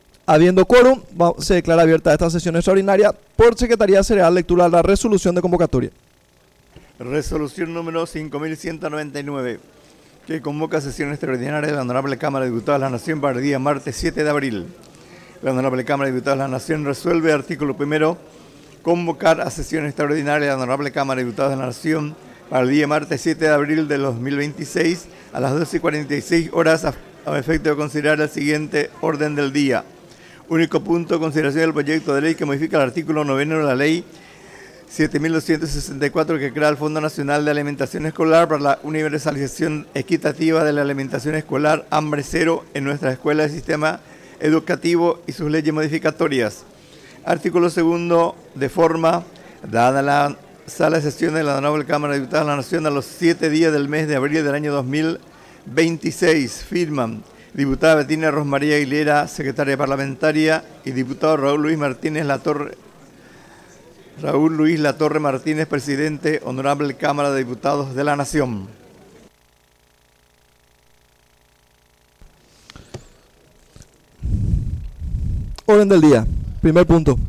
Sesión Extraordinaria – Segunda Sesión, 7 de abril de 2026